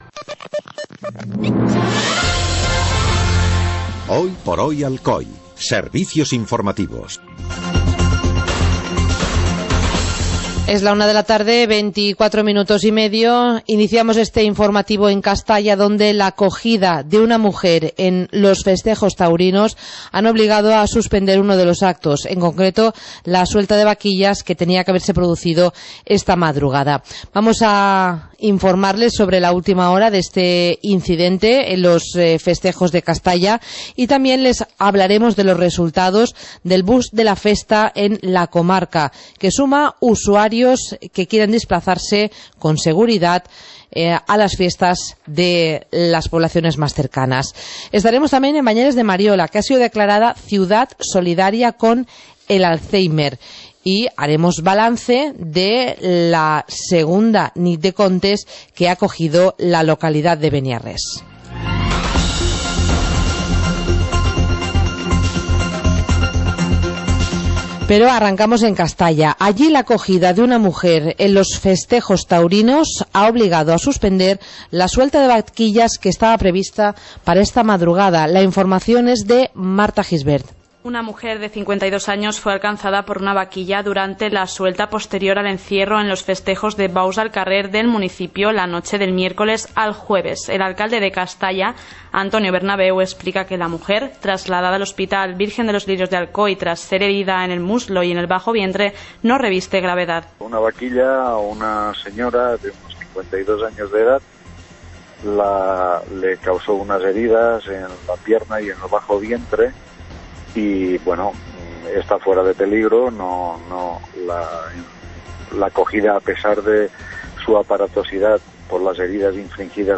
Informativo comarcal - jueves, 13 de agosto de 2015